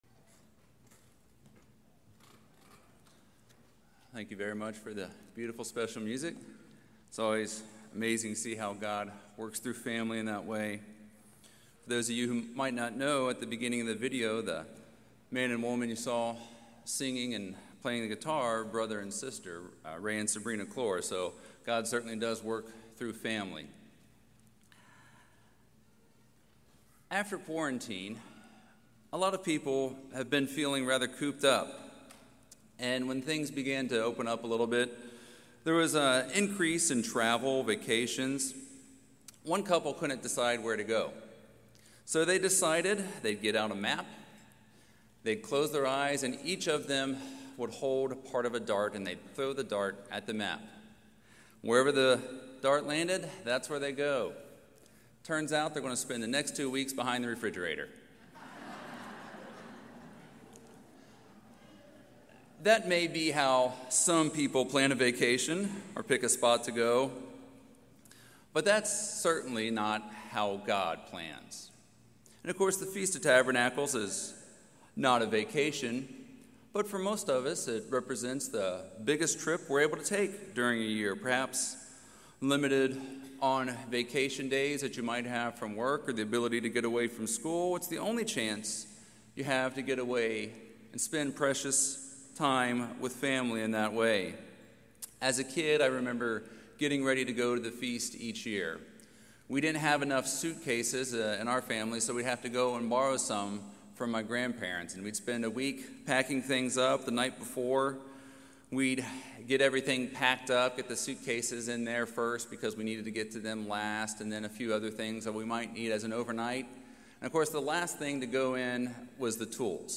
This sermon was given at the Panama City Beach, Florida 2021 Feast site.